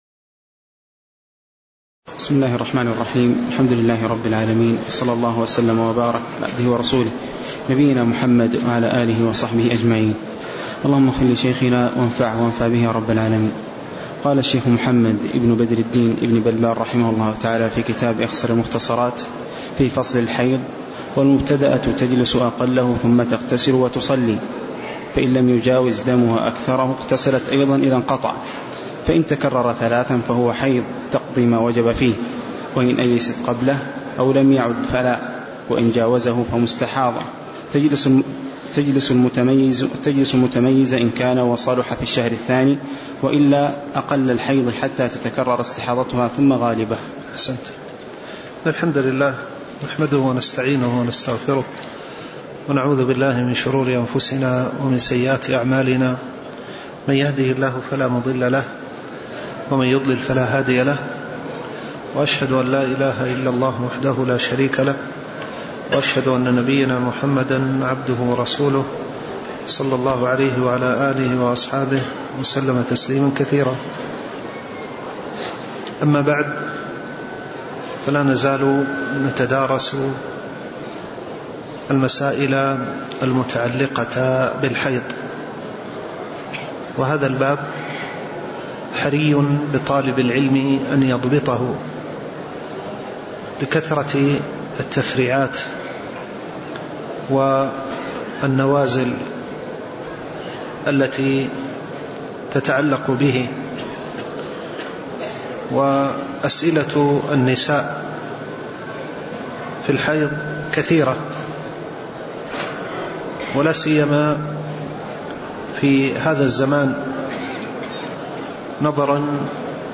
تاريخ النشر ١ ربيع الثاني ١٤٣٩ هـ المكان: المسجد النبوي الشيخ